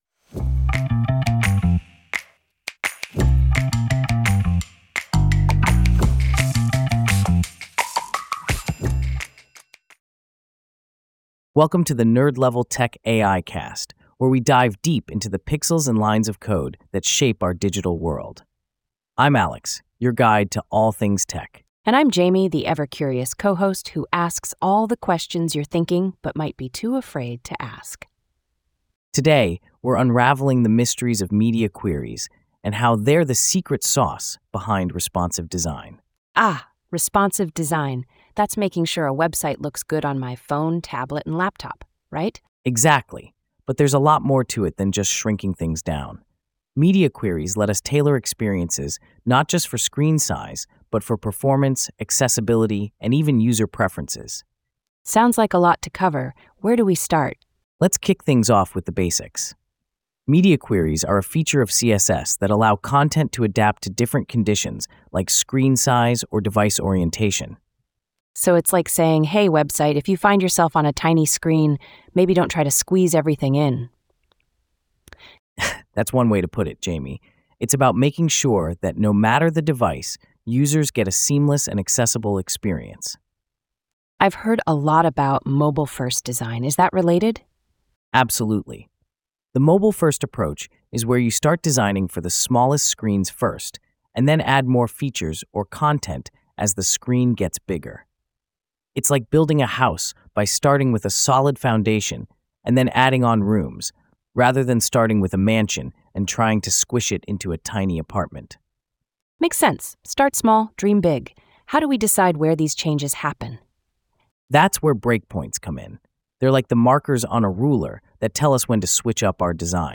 مناقشة مُولَّدة بواسطة الذكاء الاصطناعي
عن هذه الحلقة العربية (Egyptian Modern Standard):